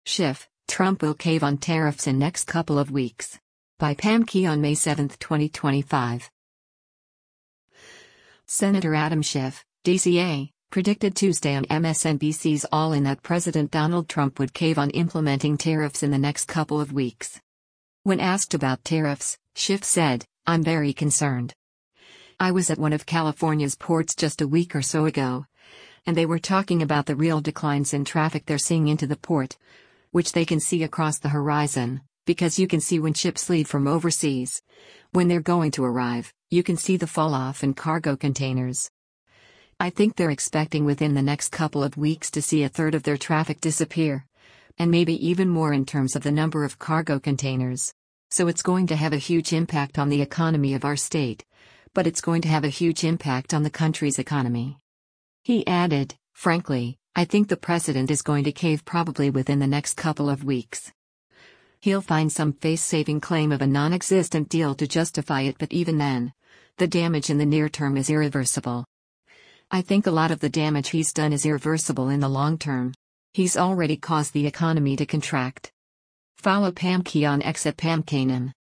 Senator Adam Schiff (D-CA) predicted Tuesday on MSNBC’s “All In” that President Donald Trump would “cave” on implementing tariffs in the next couple of weeks.